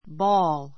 bɔ́ːl ボ ー る